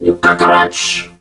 mech_crow_get_hit_01.ogg